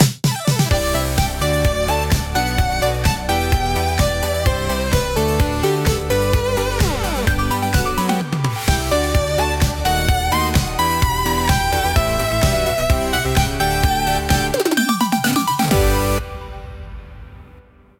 pop
当サイトの音素材は、Suno（有料プラン）または Sora（Sora 2）を利用して制作しています。